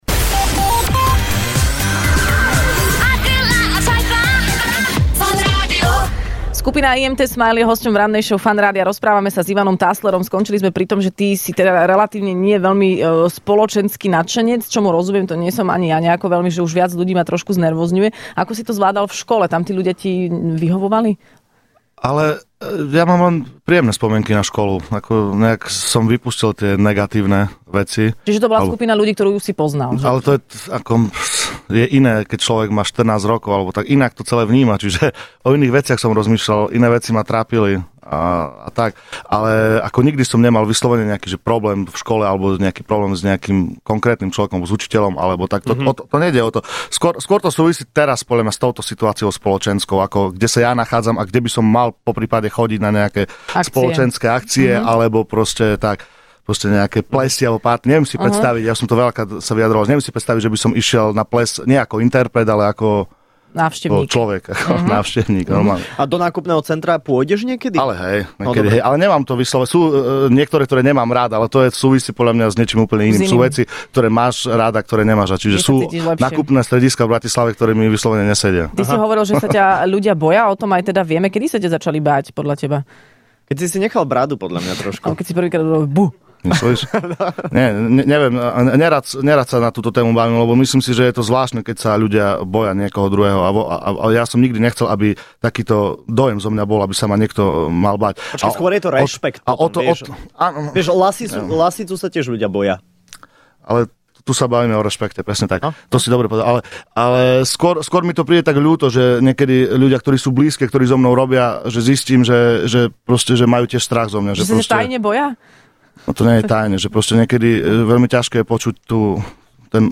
Hosťom v Rannej šou bola dnes skupina IMT Smile